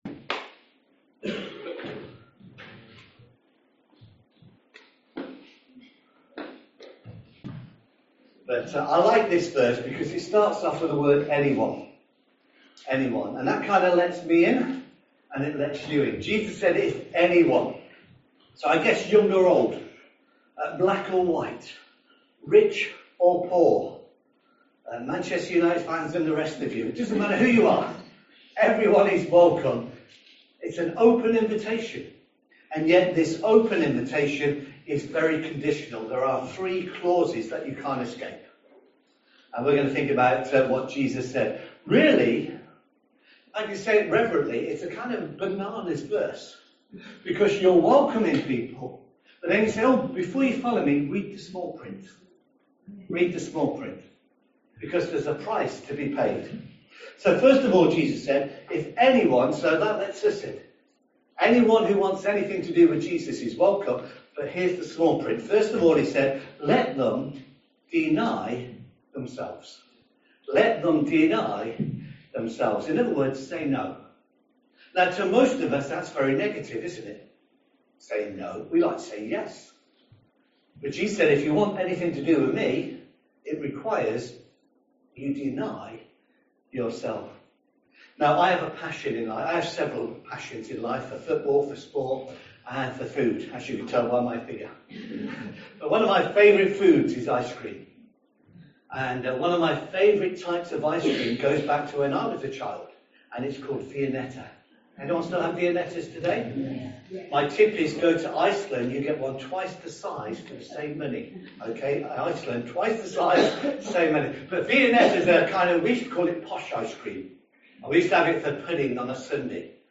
Holiday Club Service